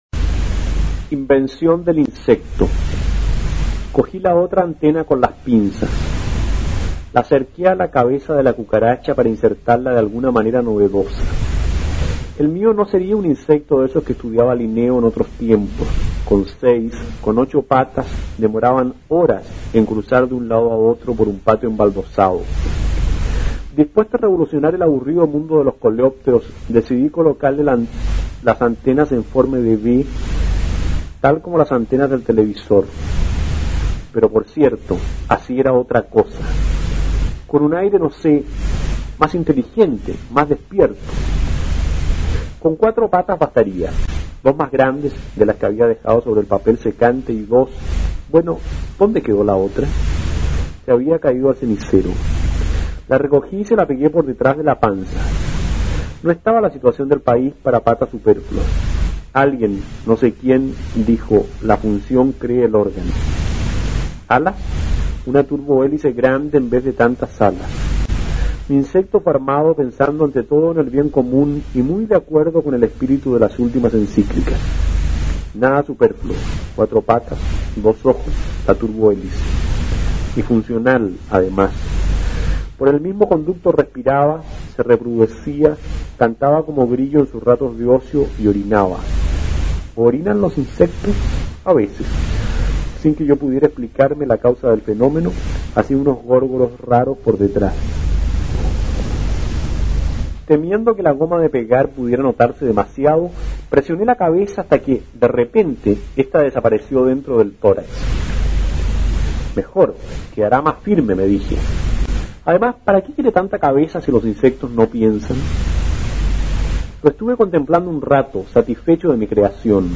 Cuento
Narración